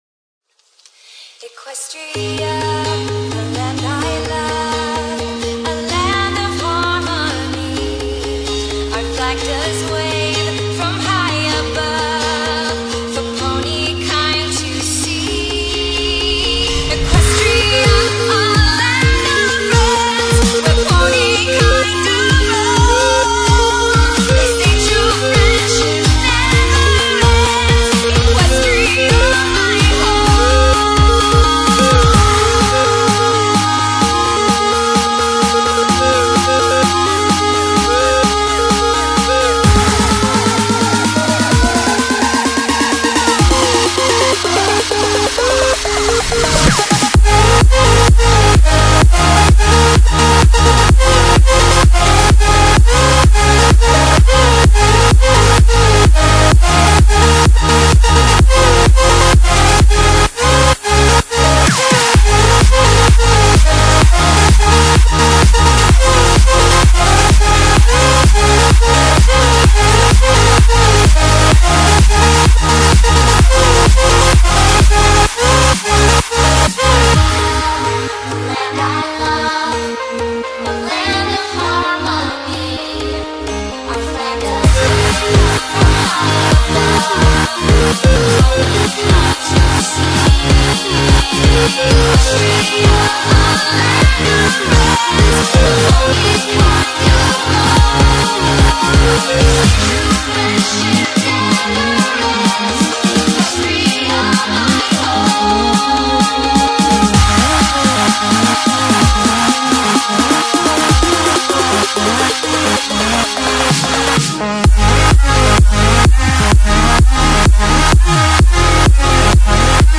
Genre : House/Electro House BPM : 128